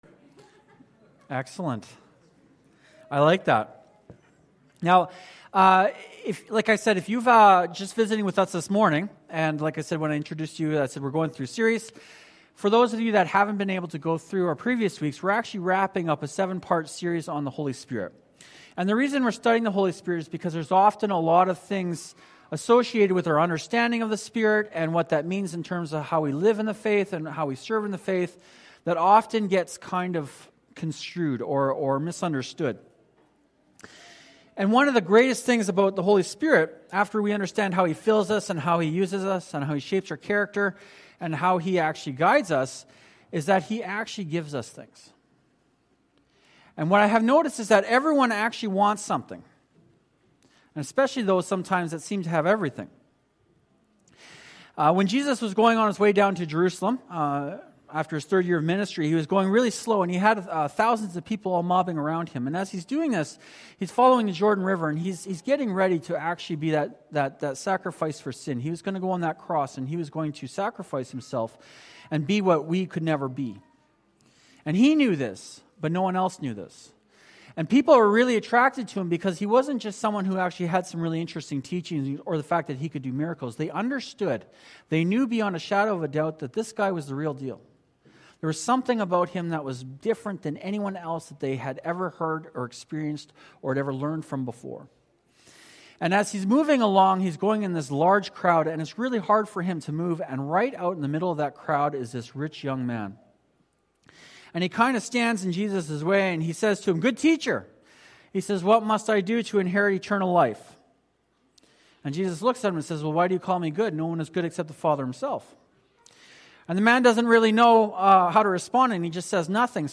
Holy Spirit: The Gift Giver – Fairview Cornerstone Baptist Church